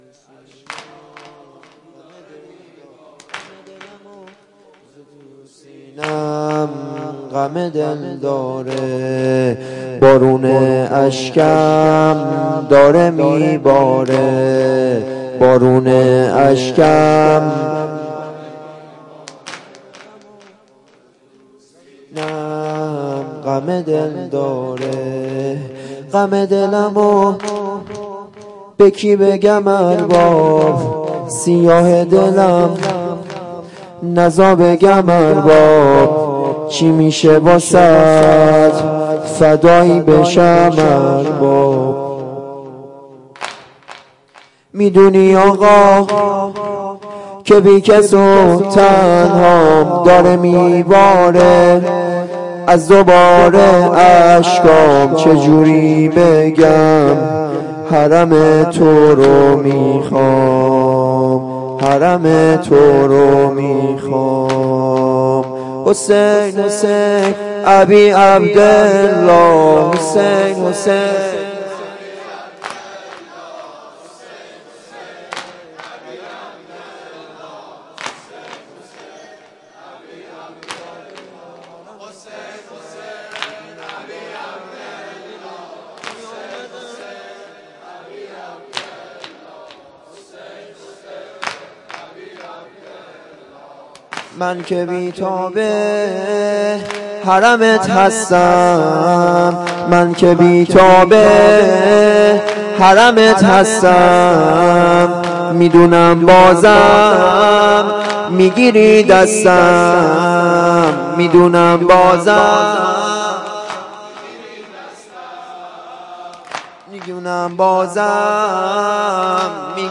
جلسه هفتگی 26-9-93.mp3